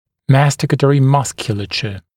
[‘mæstɪkətərɪ ‘mʌskjuləʧə][‘мэстикэтэри ‘маскйулэчэ]жевательная мускулатура, жевательные мускулы